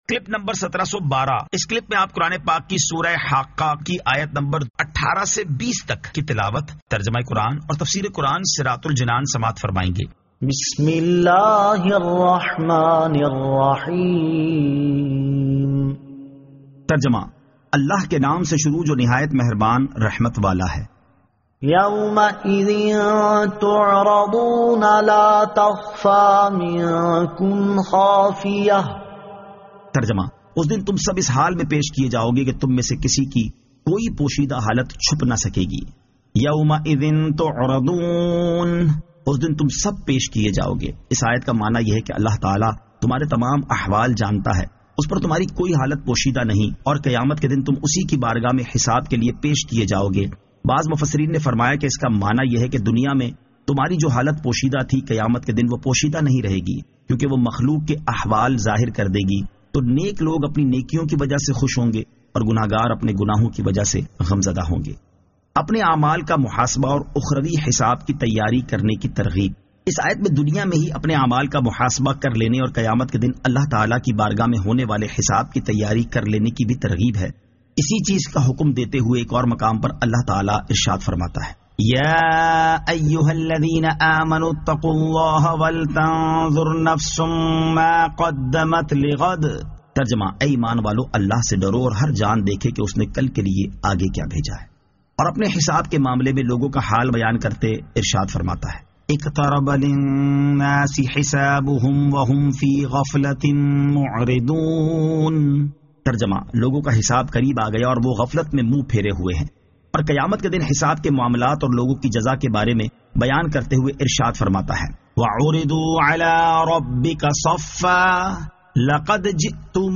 Surah Al-Haqqah 18 To 20 Tilawat , Tarjama , Tafseer